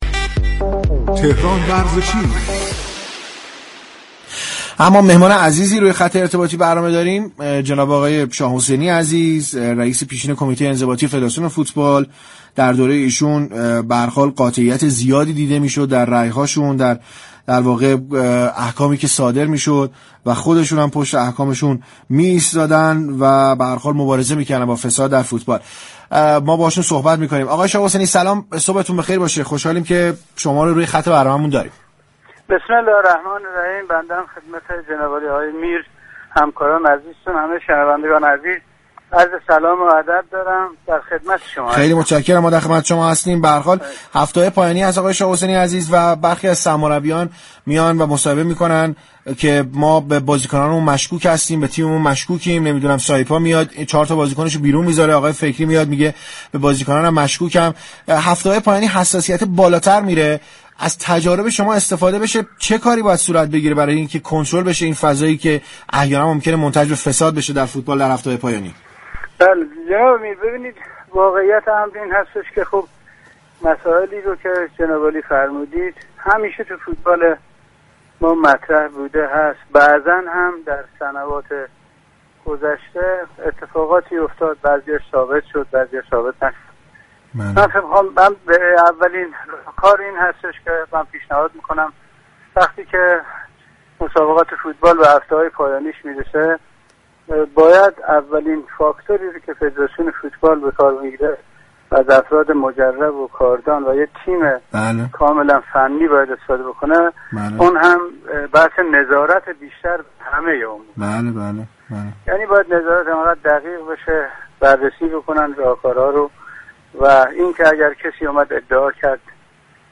در گفتگو با تهران ورزشی